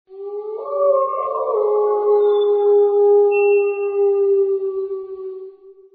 Scary Sound Effects